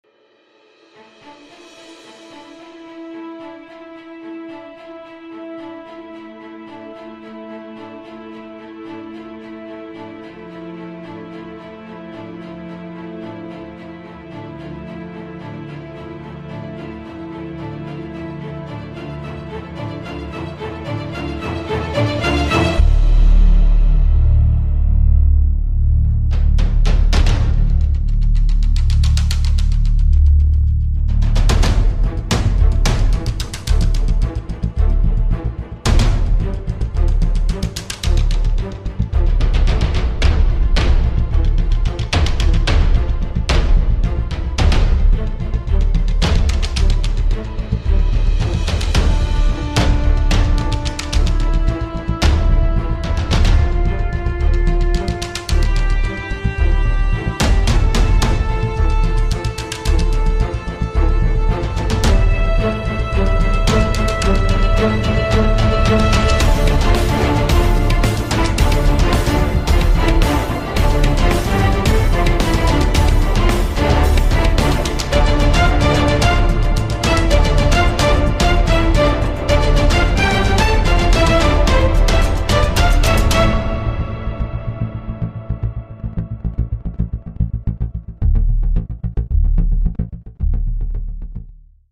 epic-suspenseful-action-music.mp3